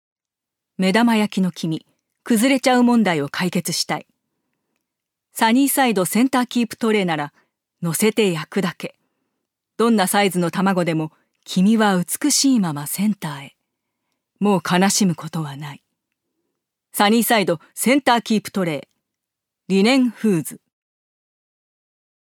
女性タレント
ナレーション６